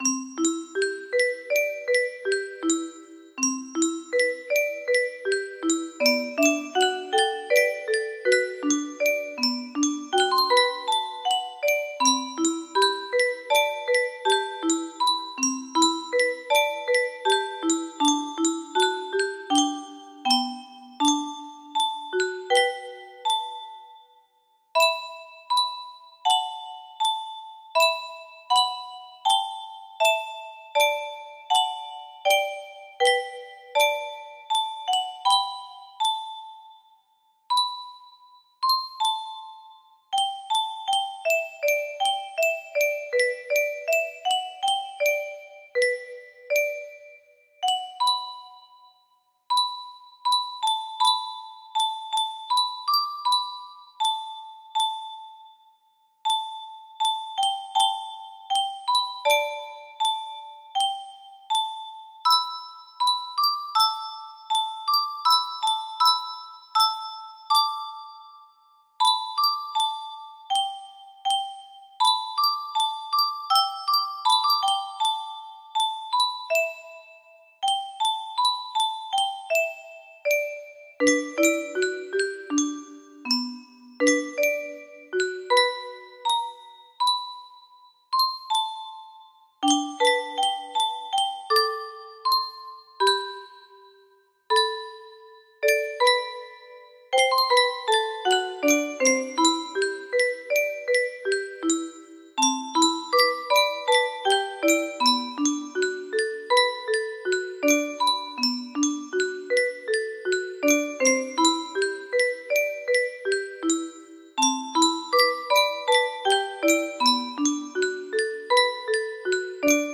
Butterfly Garden music box melody
(A song I heard in a dream once, ending my own. Some parts lost in translation to 30-note music box format.)